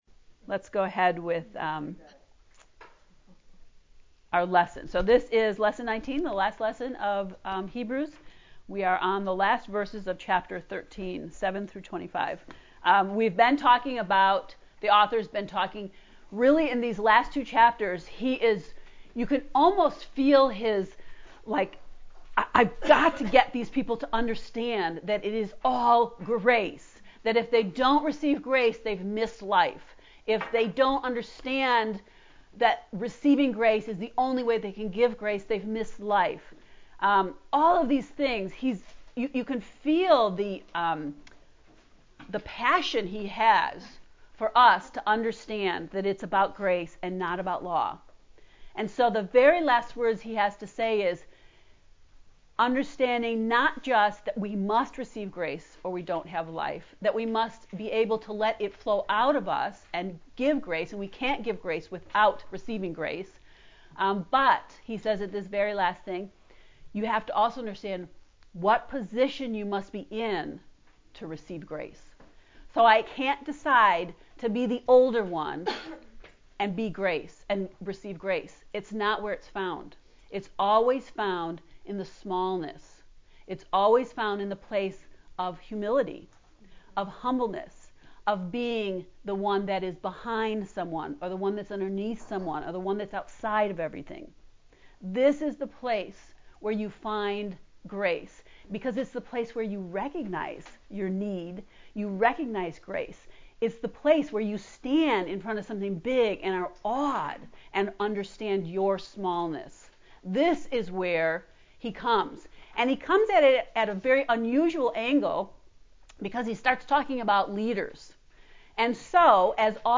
To listen to the lecture on lesson 19 “Positions of Grace”, click below:
heb-ii-lecture-19.mp3